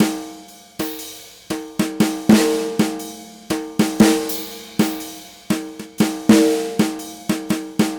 Unison Jazz - 1 - 120bpm - Tops.wav